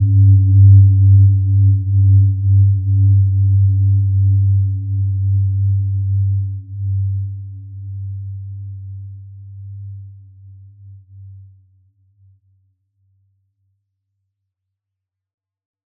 Gentle-Metallic-3-G2-p.wav